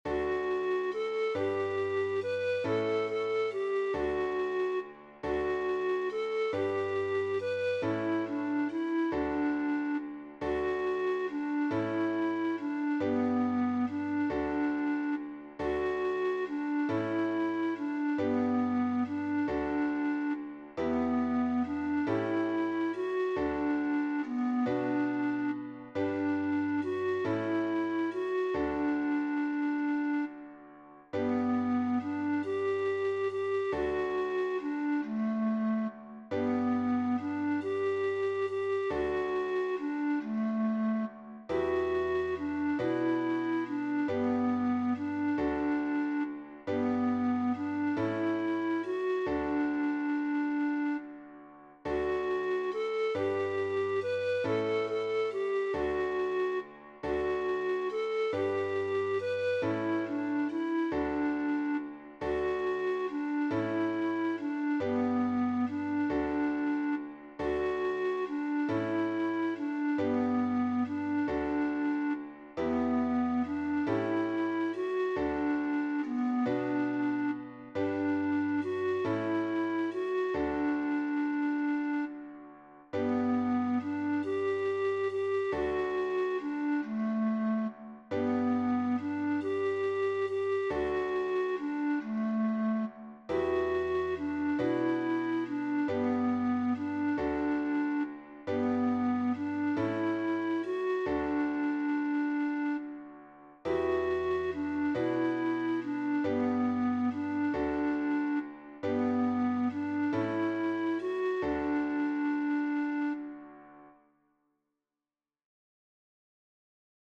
Audio midi: